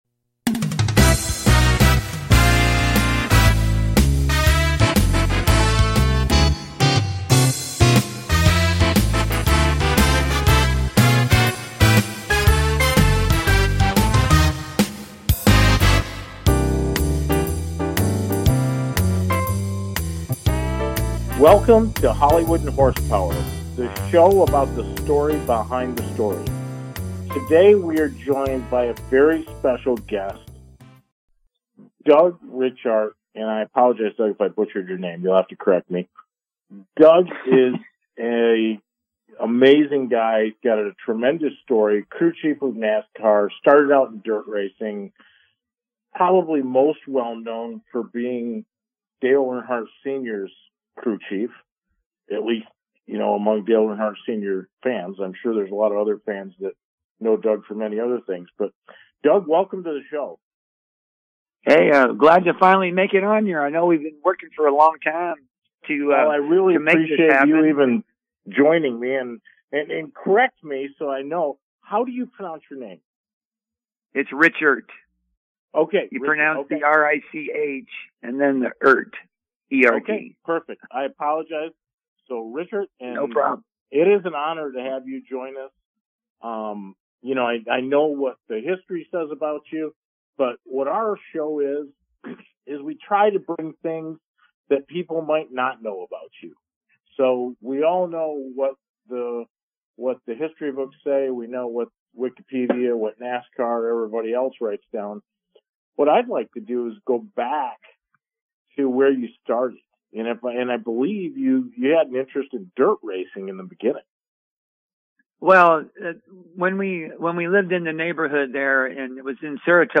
Talk Show
It is where SNL meets The Tonight Show; a perfect mix of talk and comedy.